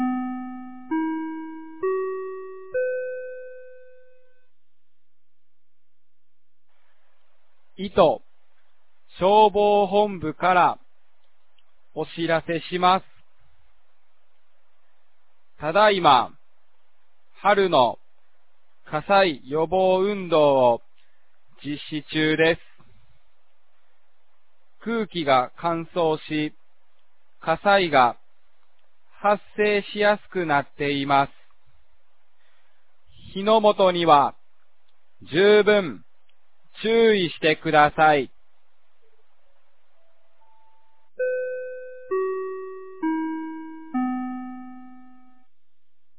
2025年03月03日 09時59分に、九度山町より全地区へ放送がありました。
放送音声